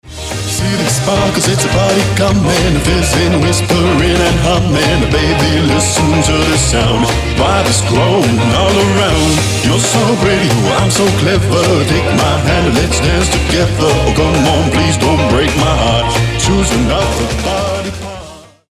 • Качество: 320, Stereo
веселые
rockabilly
РОК-Н-РОЛЛ